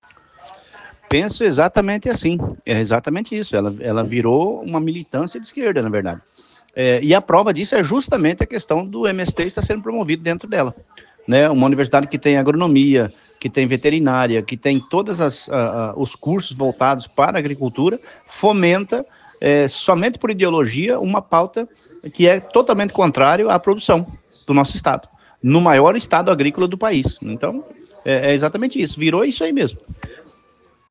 ESCUTE OS ÁUDIOS DA ENTREVISTA DADA PELO DEPUTADO GILBERTO CATTANI AO OPINIÃO MT